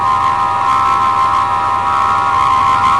saucer2.ogg